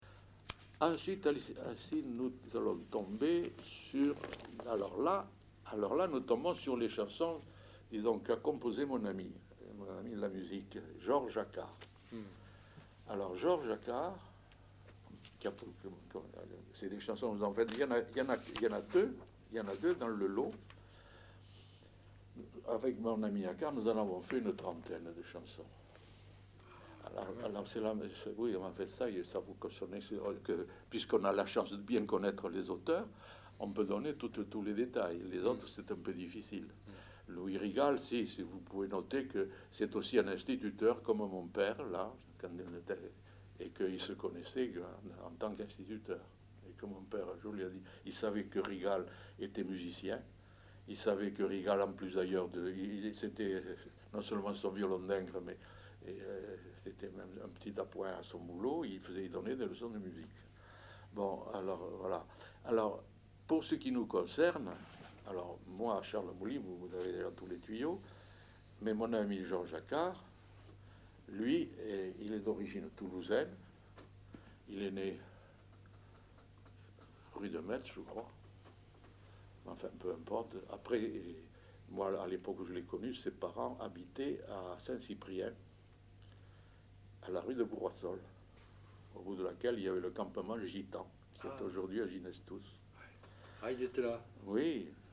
Aire culturelle : Rouergue
Lieu : Saint-Sauveur
Genre : témoignage thématique